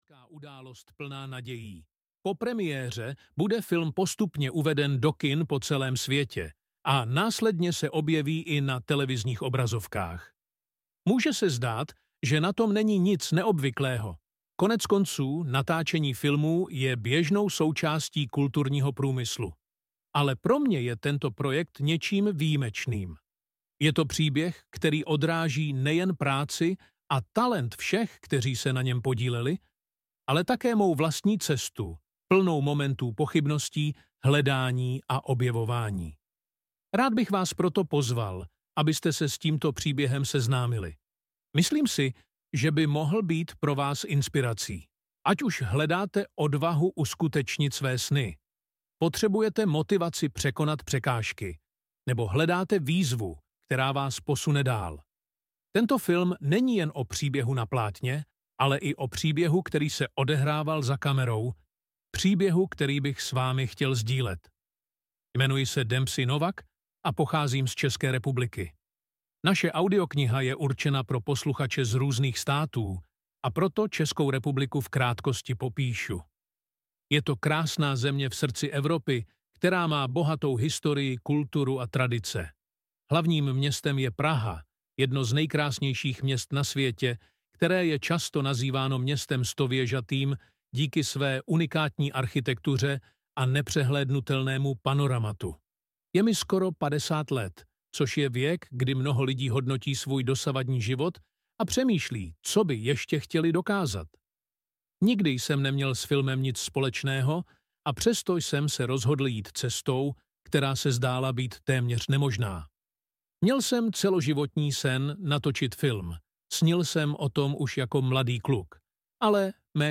Ukázka z knihy
Při tvorbě audioknihy využil hlasy vytvořené umělou inteligencí.
• InterpretUmělá inteligence